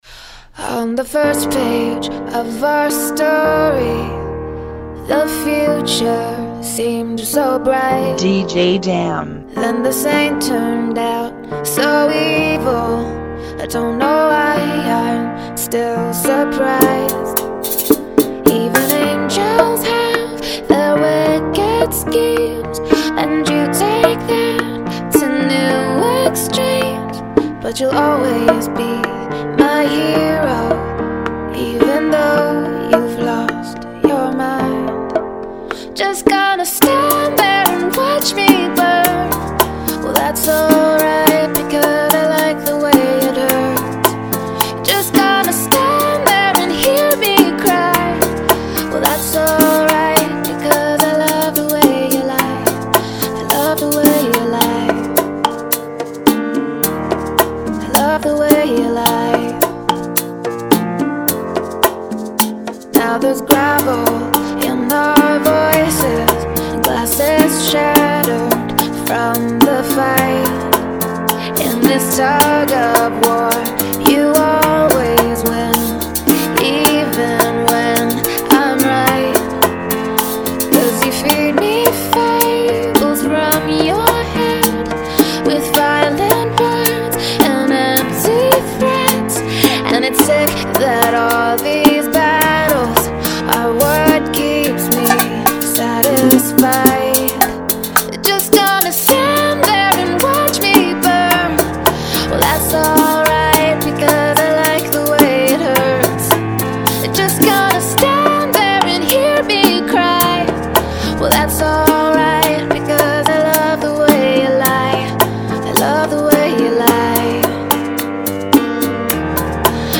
107 BPM
Genre: Bachata Remix